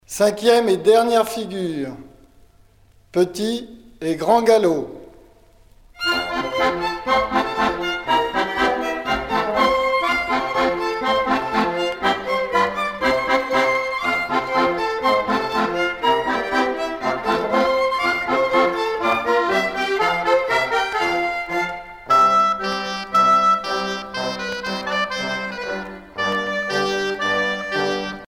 danse : quadrille : petit galop ; danse : quadrille : grand galop
Pièce musicale éditée